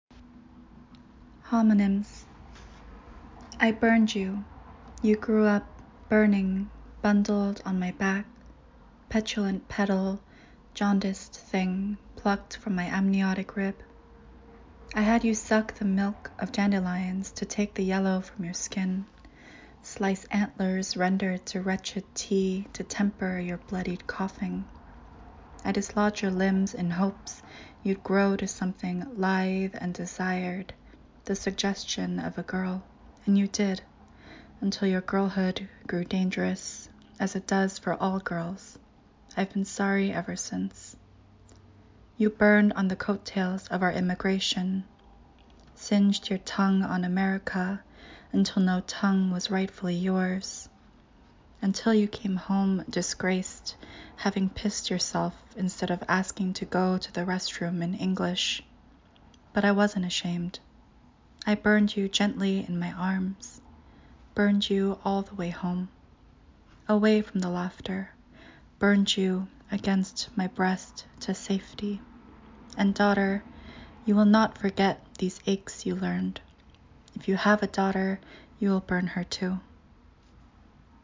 reciting a poem